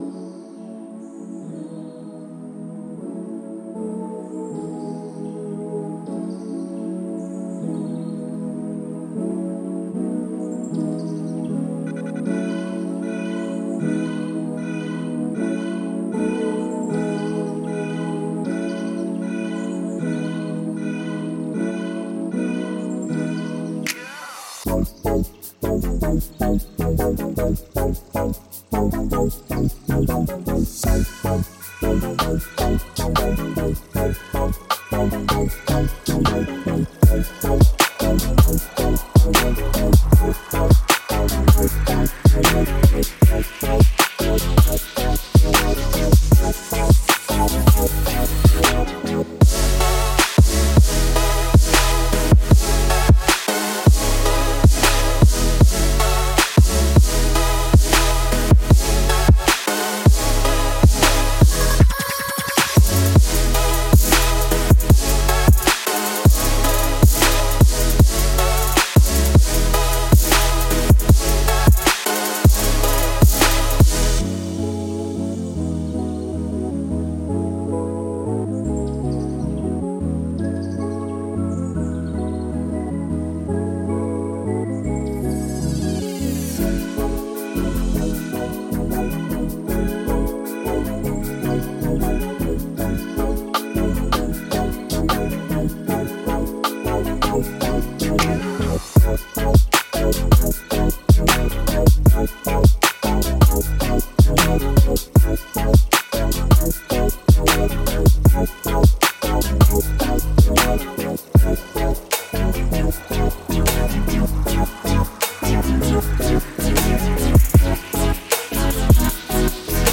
это мелодичная композиция в жанре lo-fi hip-hop